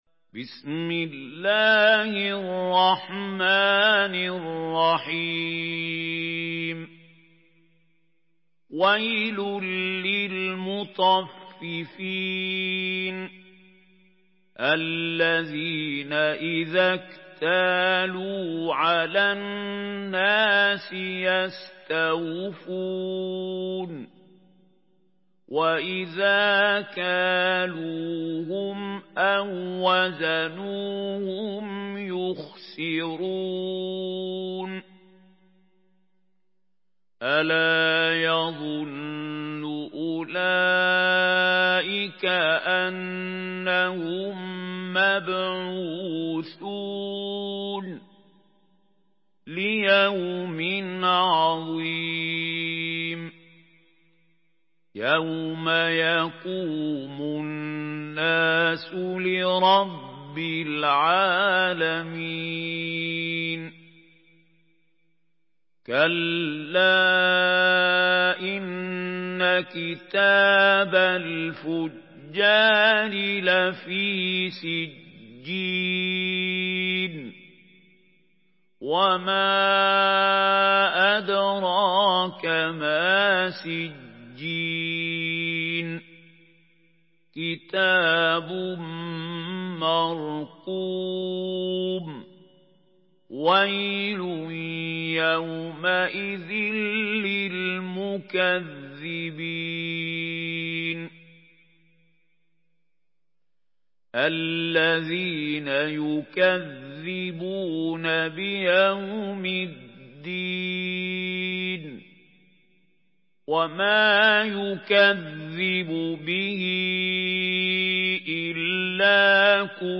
Surah Al-Mutaffifin MP3 by Mahmoud Khalil Al-Hussary in Hafs An Asim narration.
Murattal Hafs An Asim